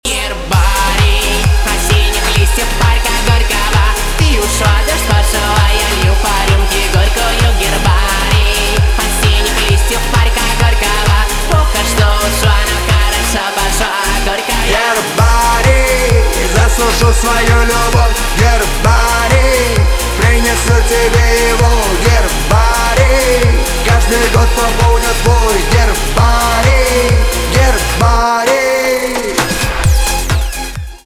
поп
битовые , басы , качающие , танцевальные
грустные